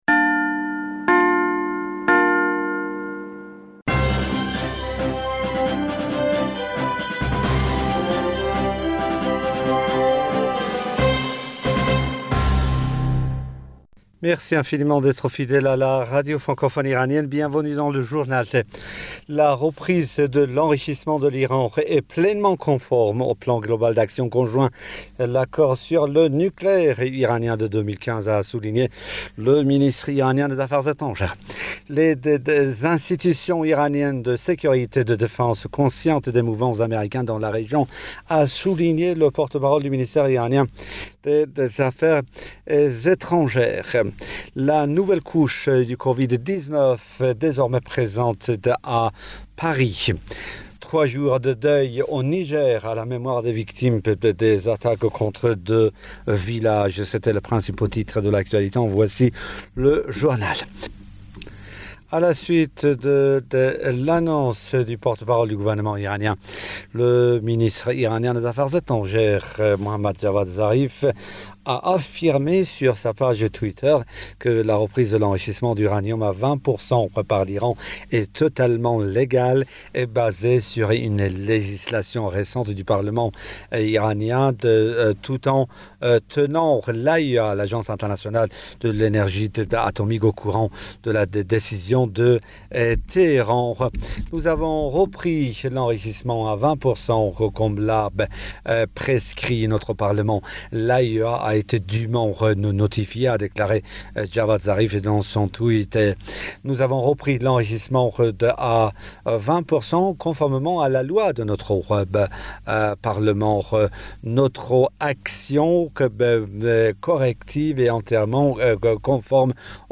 Bulletin d'informationd u 05 Janvier 2021